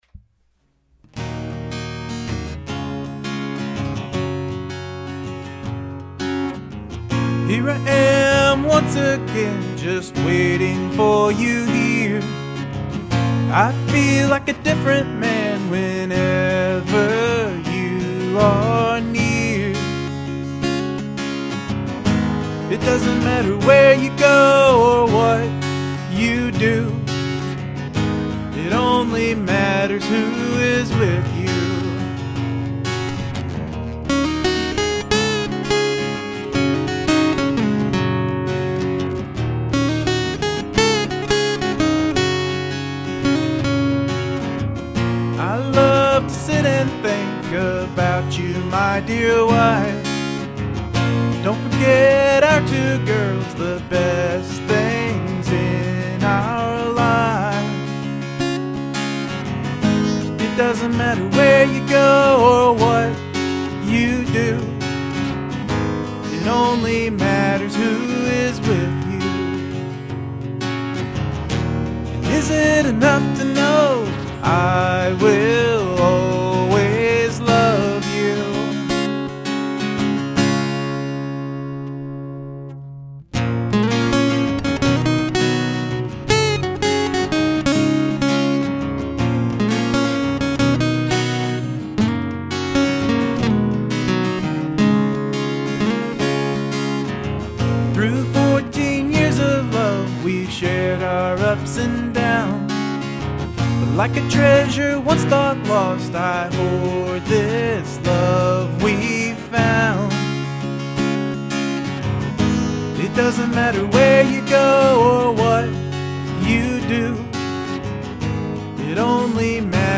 The solo is great and the chorus is in my mind!
There's a nice feel about it: emotional without being slushy.
Cool solo, and I liked the bridge work.
Nice upbeat song!!  I like your guitar solo as well!!
Guitar solo is oh so sweet.